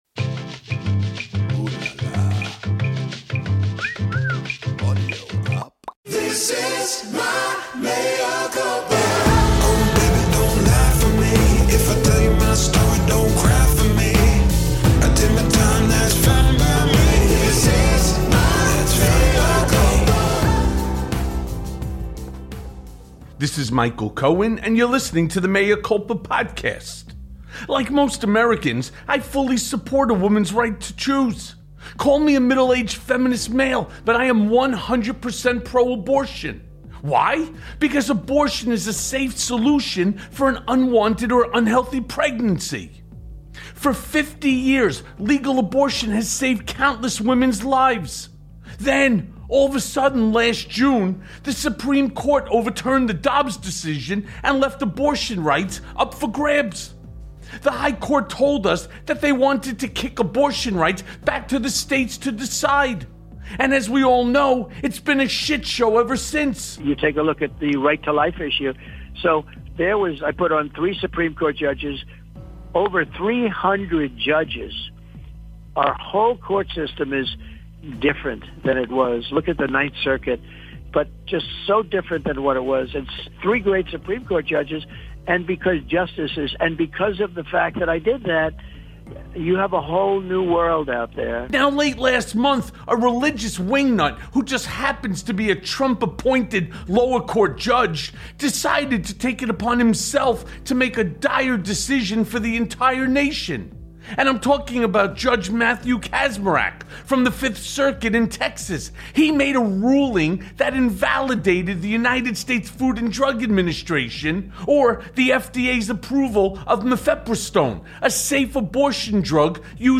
Trumps Hurls Barbs at DeSantis + A Conversation with Michael Steele